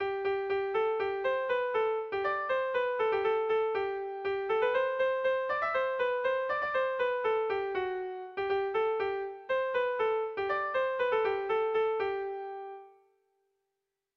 Kontakizunezkoa
ABDAB